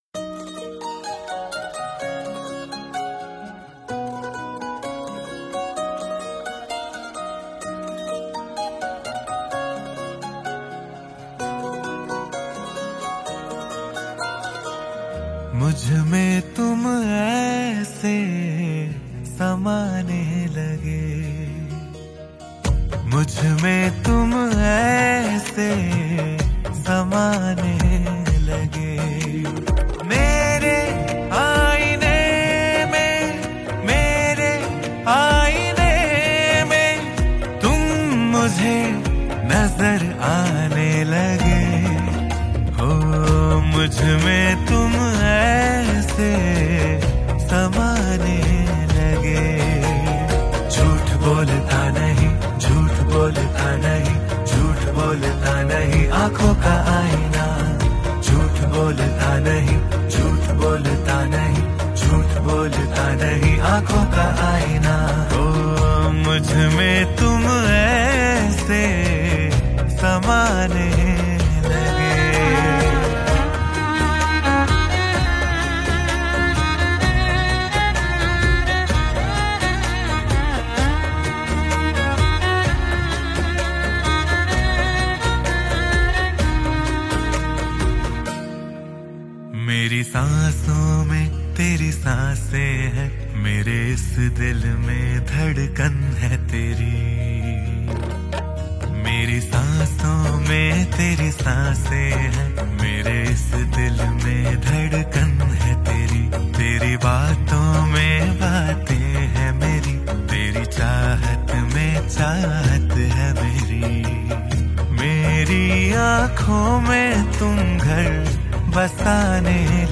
Hindi Pop Album Songs